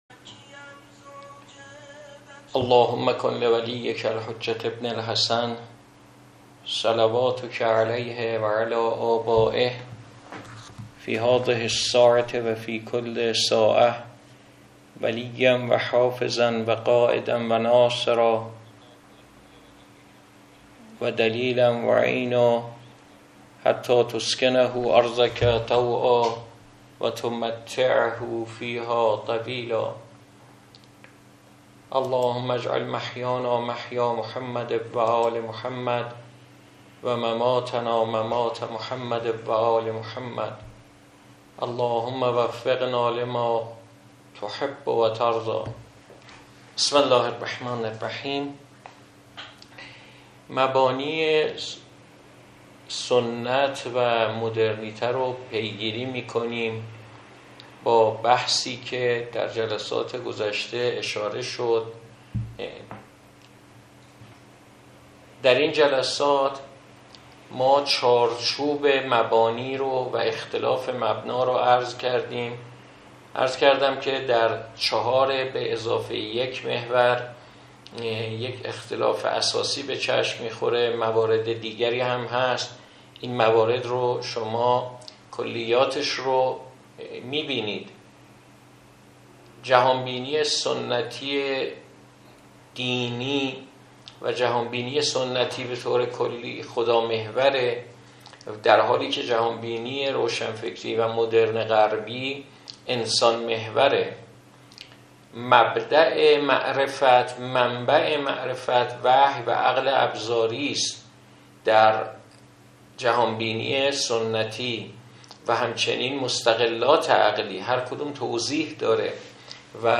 سخنرانی گردهمایی 13 آذرماه 1404